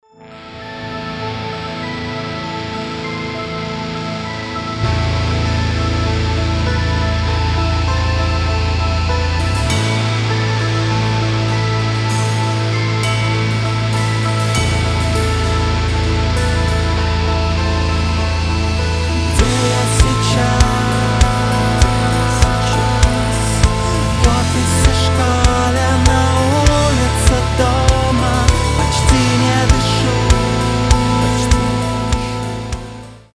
грустные
нарастающие
Post-Hardcore
мрачные
Мрачноватый трек